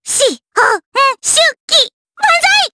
May-Vox_Skill6_jp.wav